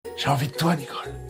Texture-Pack/assets/minecraft/sounds/mob/zombie/wood3.ogg at master
spatial sound